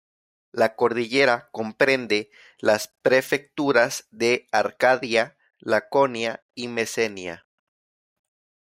cor‧di‧lle‧ra
/koɾdiˈʝeɾa/